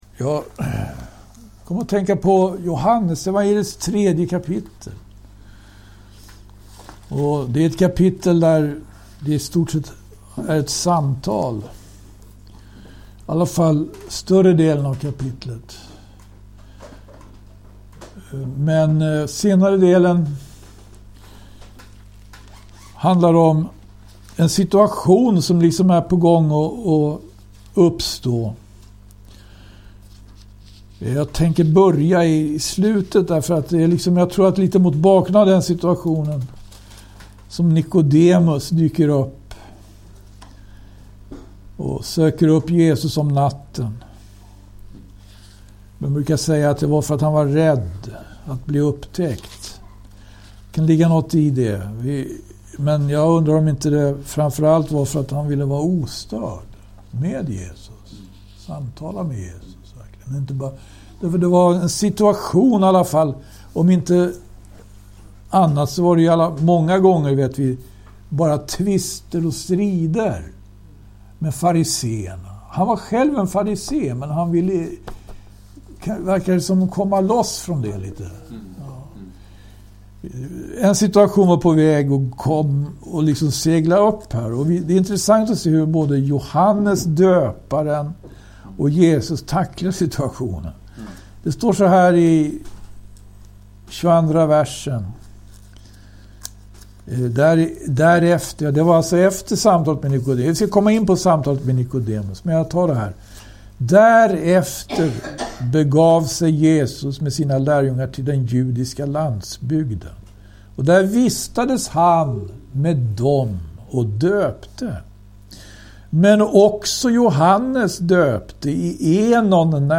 bibelstudium